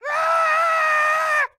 Minecraft Version Minecraft Version snapshot Latest Release | Latest Snapshot snapshot / assets / minecraft / sounds / mob / goat / scream6.ogg Compare With Compare With Latest Release | Latest Snapshot
scream6.ogg